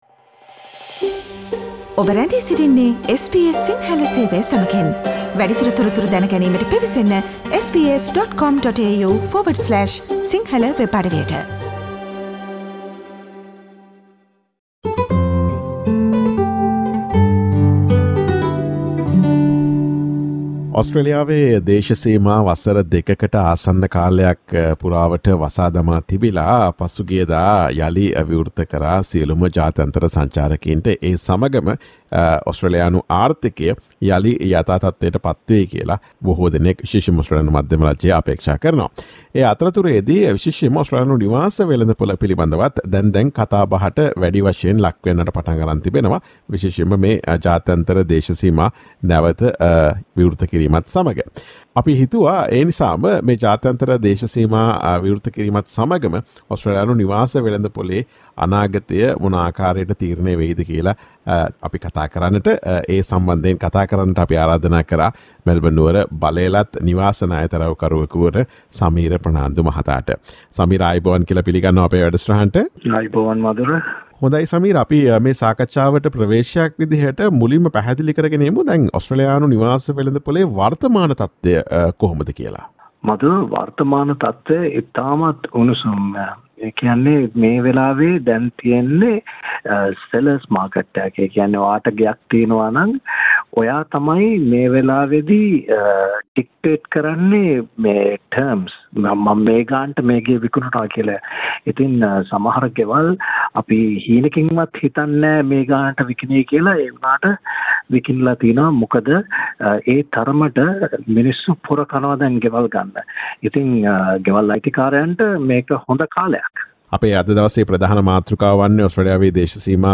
ඔස්ට්‍රේලියාවේ දේශසීමා යළි විවෘත කිරීමත් සමඟ ඔස්ට්‍රේලියානු නිවාස වෙළඳපොලේ ඉදිරිය පිළිබඳ පිළිබඳ SBS සිංහල ගුවන් විදුලිය සිදුකළ සාකච්ඡාවට සවන් දෙන්න.